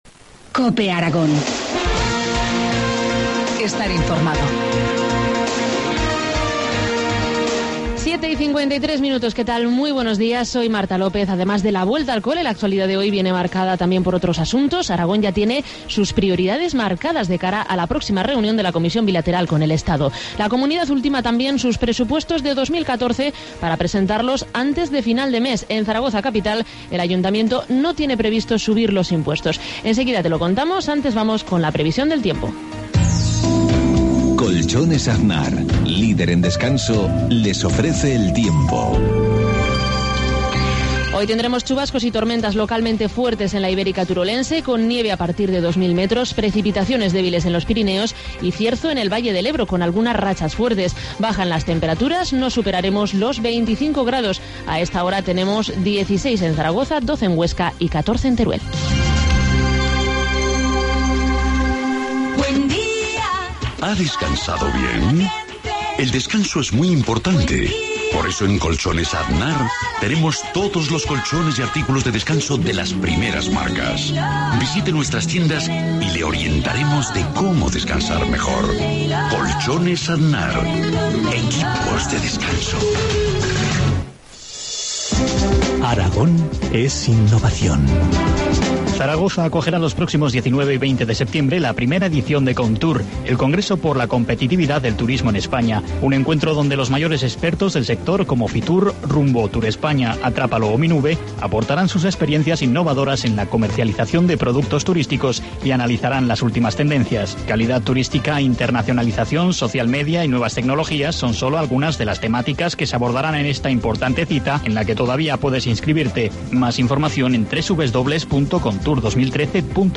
Informativo matinal, miercoles 11 septiembre, 2013, 7,53 horas